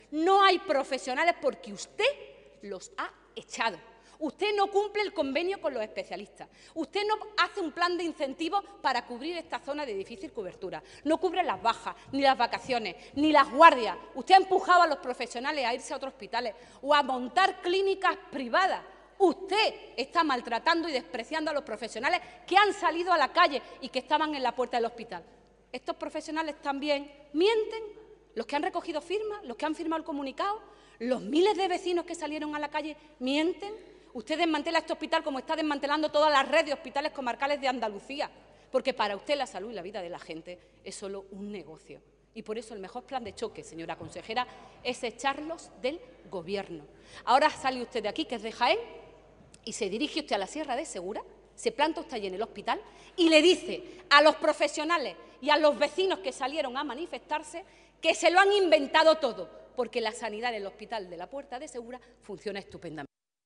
En el Parlamento de Andalucía
Cortes de sonido